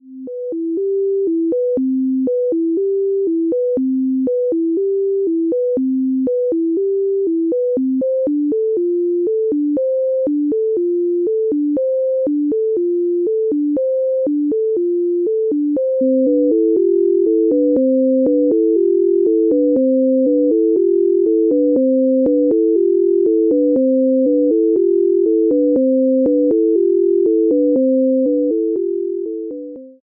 Hierbei werden erst zwei Melodien einzeln, dann gleichzeitig abgespielt. Obwohl die eine Melodie nur auf dem linken Ohr ist und die andere auf dem rechten, verbinden sich beide zu einer Neuen. Am besten verwenden Sie Kopfhörer:
neu_Deutschs_Scale_Audio_Illusion.mp3